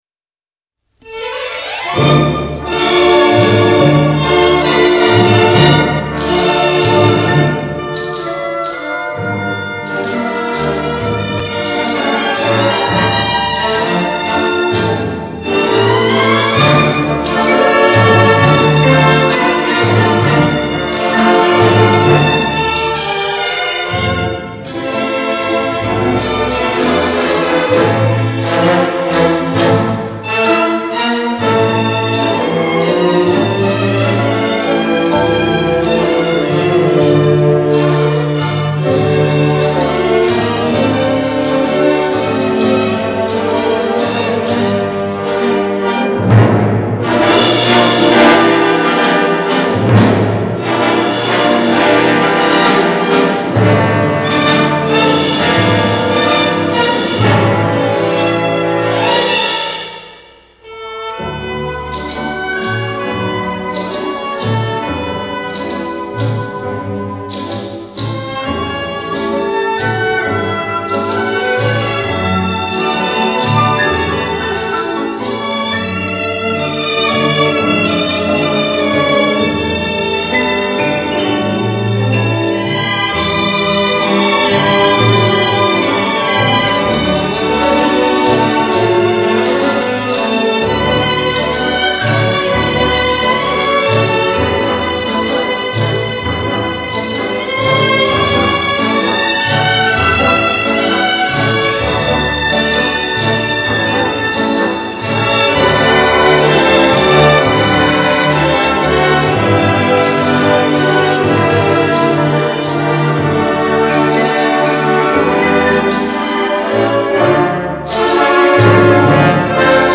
Genre: Tango, Latin